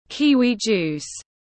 Nước ép kiwi tiếng anh gọi là kiwi juice, phiên âm tiếng anh đọc là /ˈkiː.wiː ˌdʒuːs/
Kiwi juice /ˈkiː.wiː ˌdʒuːs/